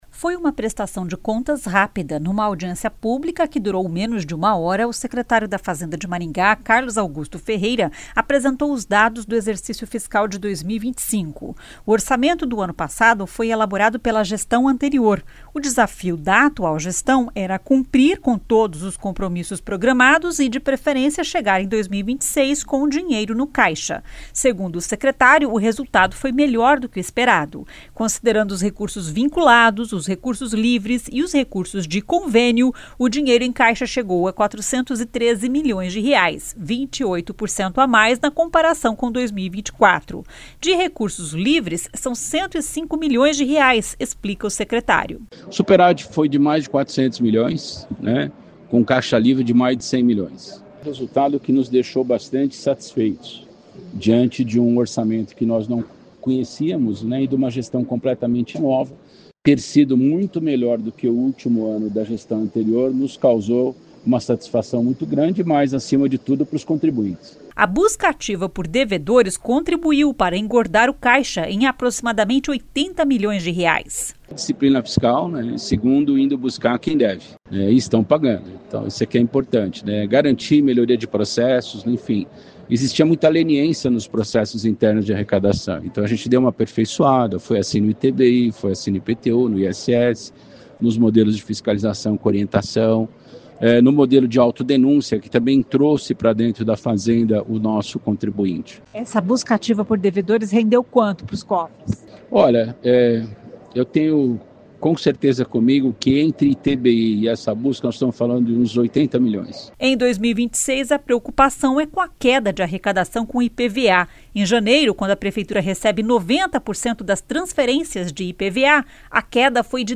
De recursos livres, são R$ 105 milhões, explica o secretário.
O presidente da Comissão de Finanças e Orçamento (CFO) da Câmara Municipal, vereador Sidnei Telles, diz que a prestação de contas foi objetiva.